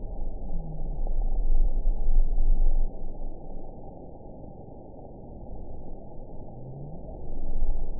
event 919285 date 12/29/23 time 22:51:14 GMT (1 year, 5 months ago) score 8.20 location TSS-AB07 detected by nrw target species NRW annotations +NRW Spectrogram: Frequency (kHz) vs. Time (s) audio not available .wav